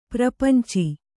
♪ prapanci